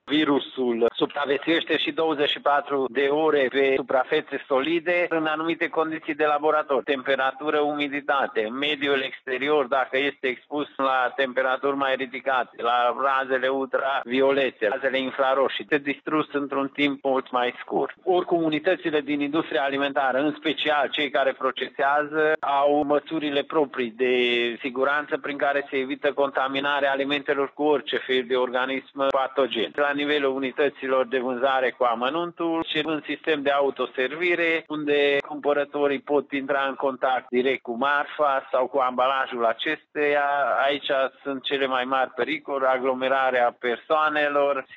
Cu detalii vine directorul DSVSA Mureș, Kincses Sandor: